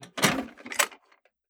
Ammo Crate Open 003.wav